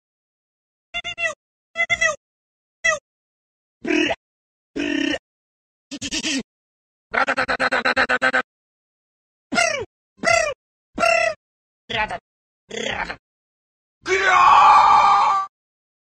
Funny Gun Troll Sound Effect Free Download